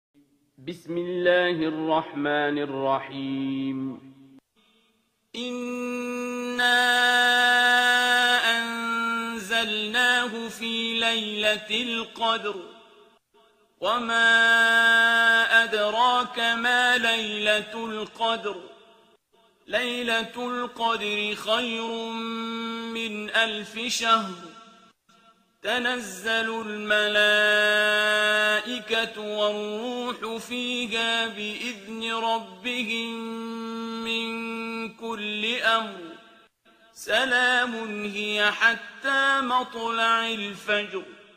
ترتیل سوره قدر با صدای عبدالباسط عبدالصمد